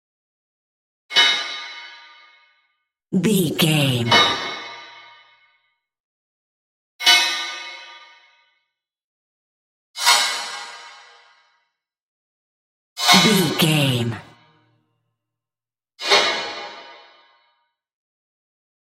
Metal hit
Sound Effects
urban
hard